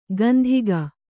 ♪ gandhiga